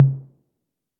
pause-continue-click.ogg